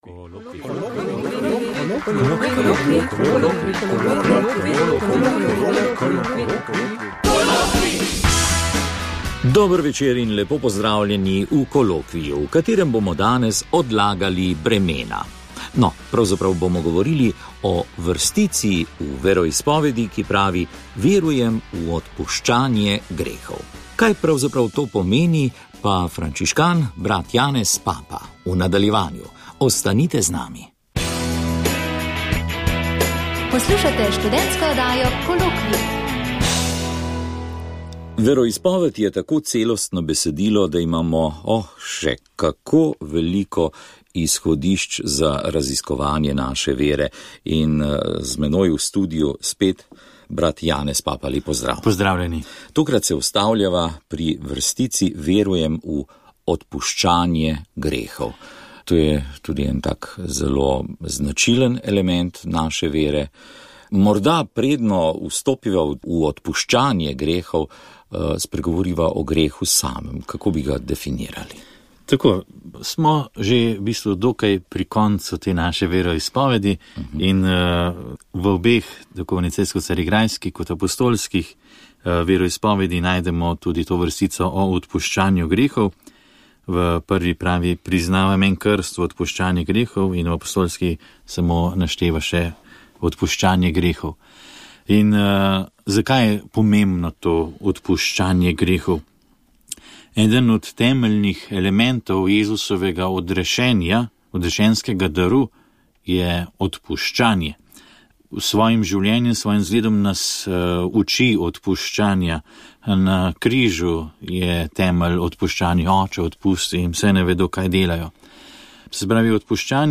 Pogovor o VEROIZPOVEDI
V letu 2019 bo v oddaji Kolokvij na radiu Ognjišče, predvidoma vsak drugi mesec na 3. petek v mesecu ob 21. uri, potekal pogovor in razmišljanje o katoliški VEROIZPOVEDI.